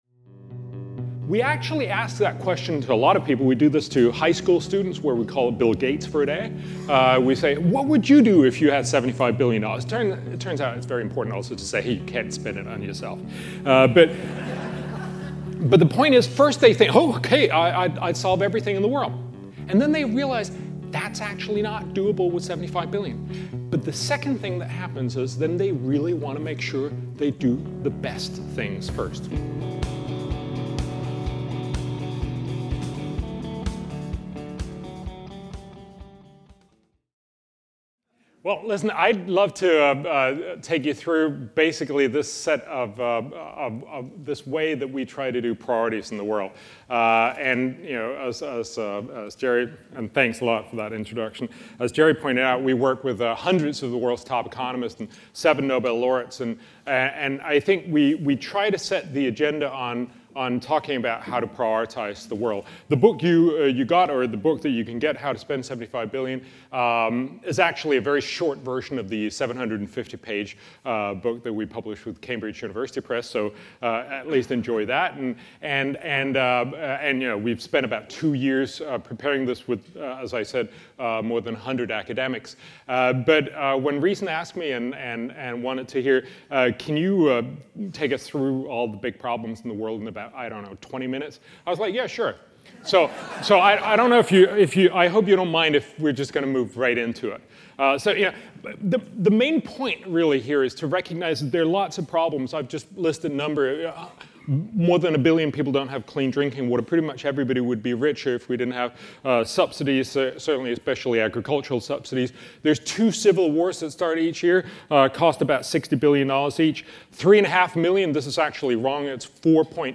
If not, what do you fix first? Bjørn Lomborg—a Danish writer, Copenhagen Business School adjunct professor, director of the Copenhagen Consensus Centre, and former director of the Environmental Assessment Institute in Copenhagen—speaks to Reason Weekend attendees about solving the world's problems.